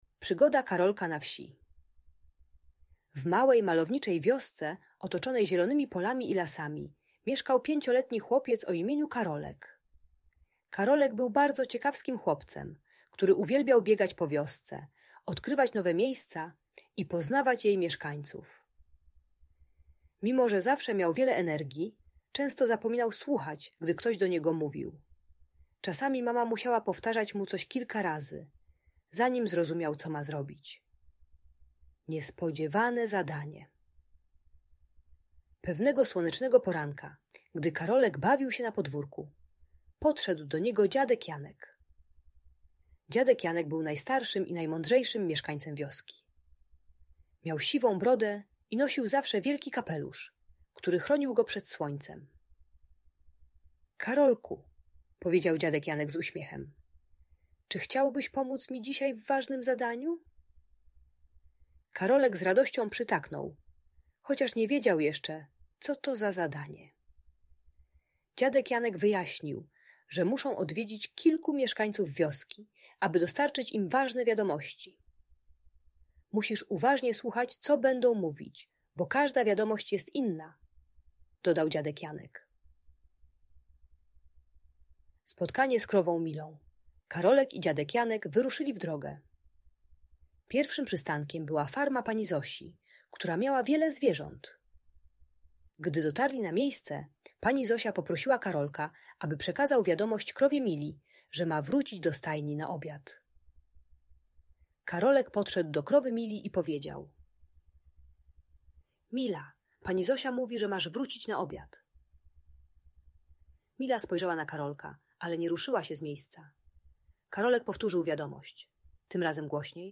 Przygoda Karolka na Wsi - historia o słuchaniu - Audiobajka